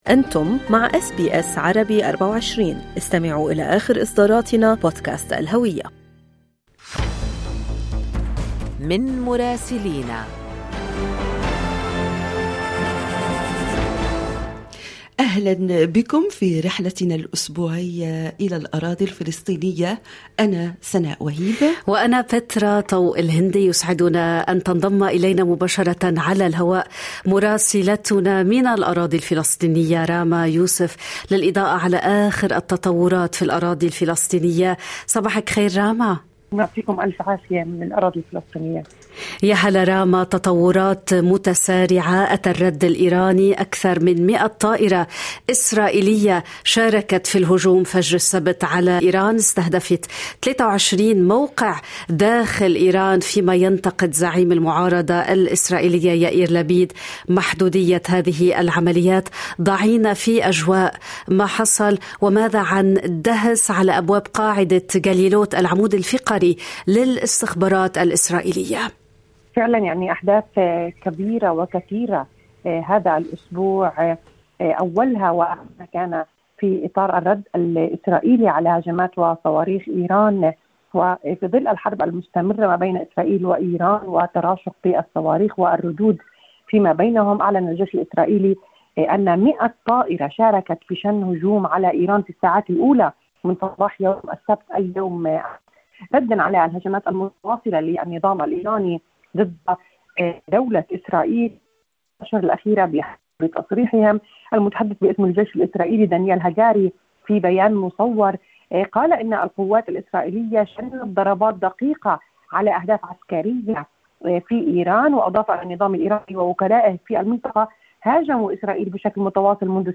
كما وتتطرق الى الواقع الميداني في الضفة الغربية مع تعرض أهالي الضفة لمداهمة منازلهم وتدمير البنى التحتية في عمليات عسكرية إسرائيلية منظمة، ما قد يحول الضفة الغربية إلى غزة ثانية. يمكنكم الاستماع إلى التقرير الصوتي من رام الله بالضغط على التسجيل الصوتي أعلاه.